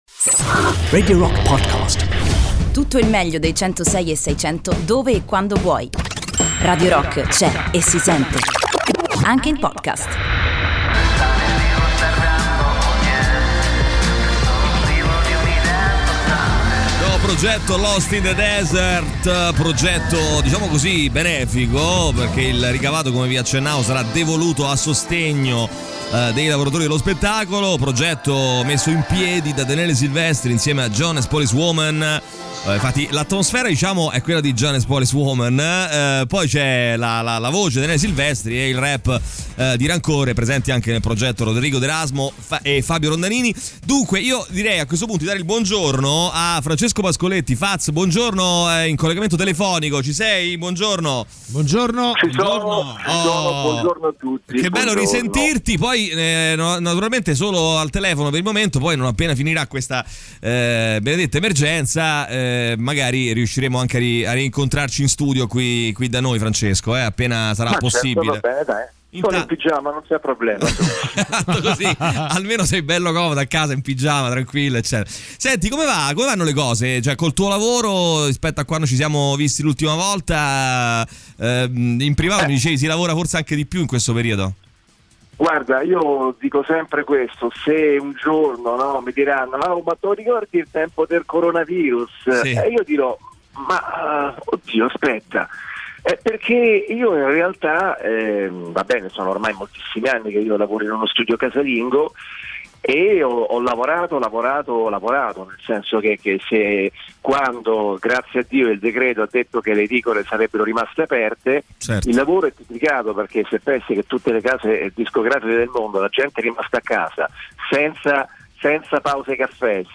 Intervista
in collegamento telefonico